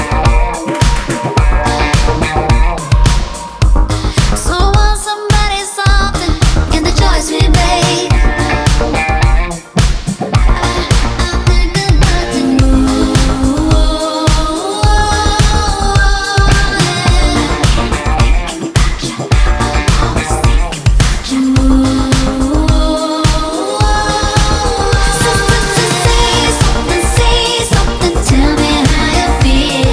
• Pop
It was recorded in Brighton, England.